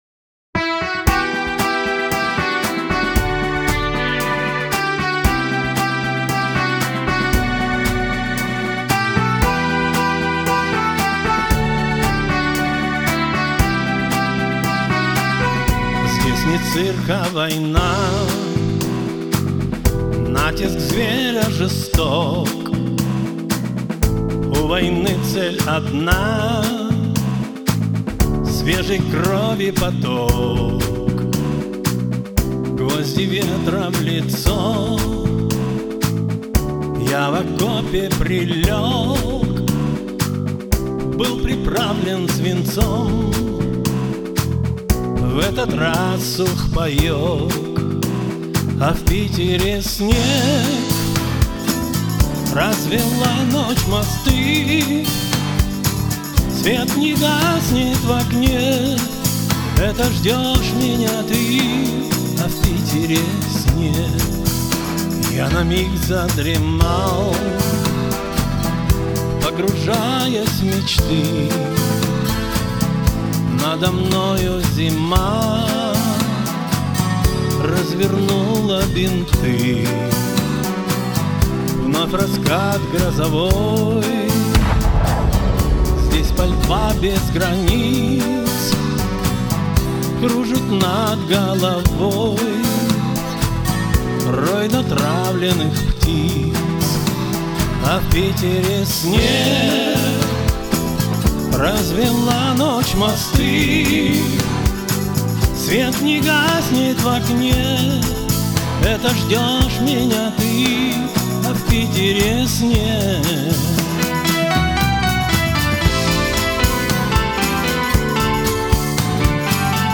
грусть , эстрада